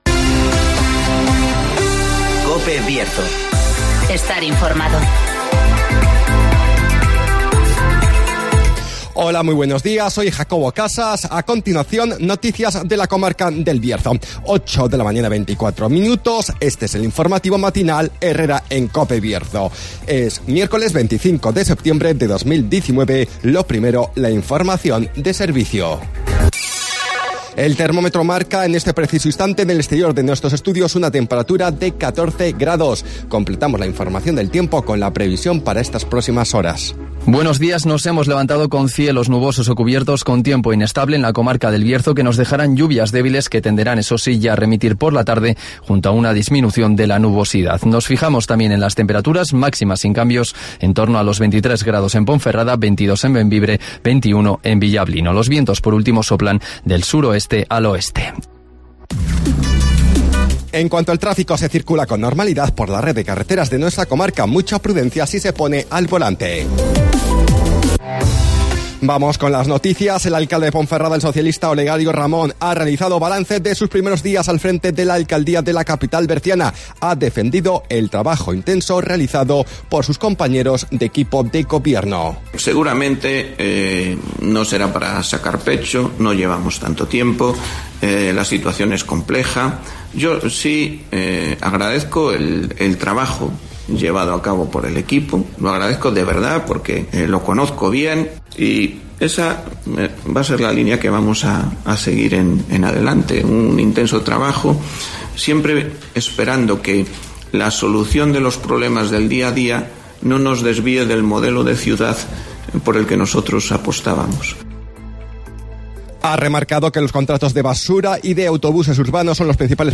INFORMATIVOS BIERZO
-Conocemos las noticias de las últimas horas de nuestra comarca, con las voces de los protagonistas